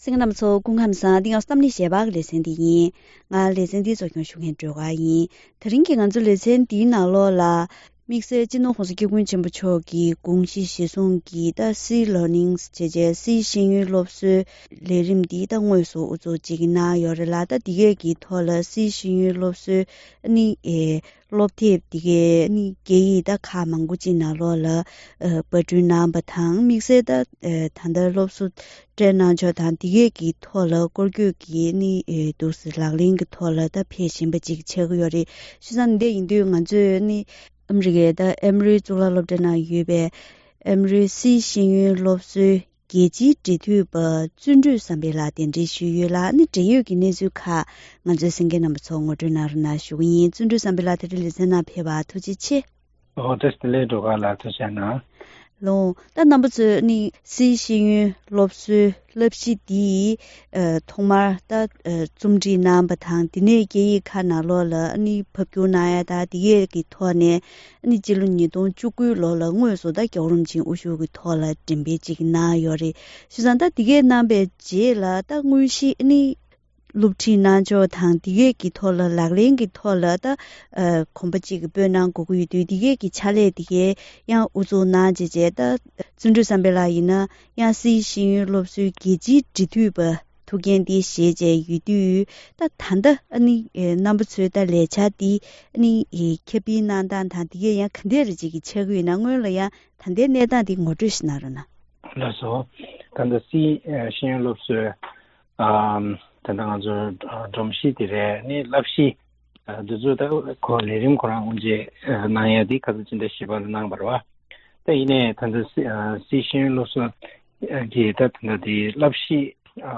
སཱི་ཤེས་ཡོན་སློབ་གསོ་ཞེས་པའི་སློབ་གསོ་གནང་ཕྱོགས་སོགས་ཀྱི་འབྲེལ་ཡོད་སྐོར་ལ་བཀའ་མོལ་ཞུས་པ་ཞིག་གསན་རོགས་གནང་།